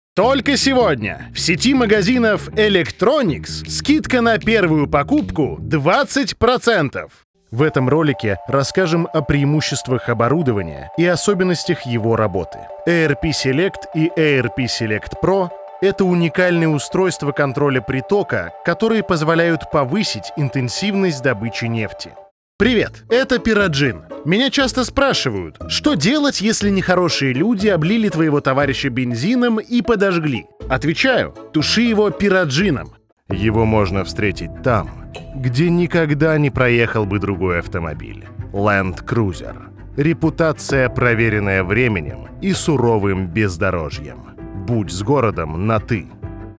Микрофон: Shure SM7B Аудиокарта: Audient iD4